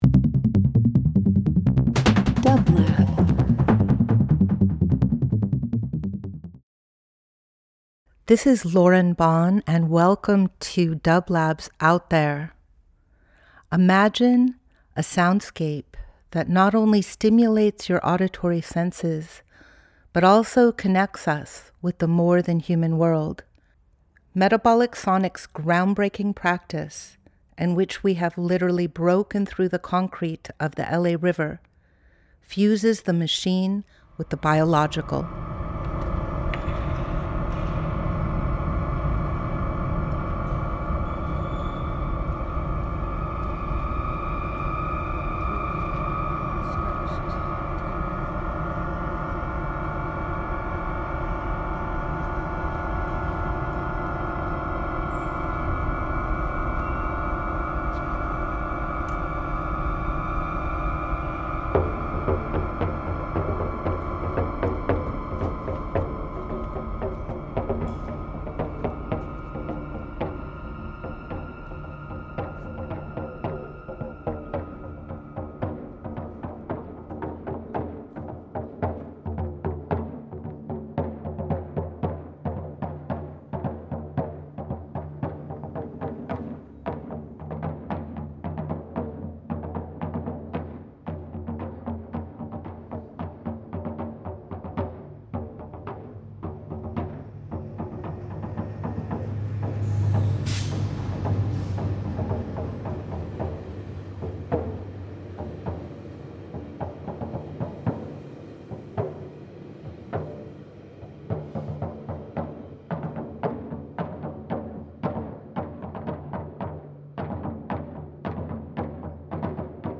Out There ~ a field recording program
Each week we present field recordings of Metabolic’s current projects as well as archival material from past ventures.